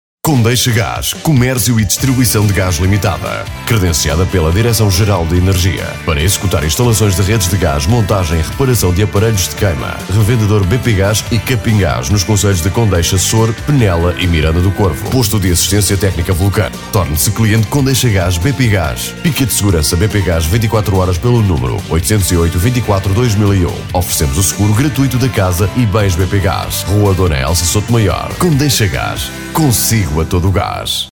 1ª - Campanha  Publicitária.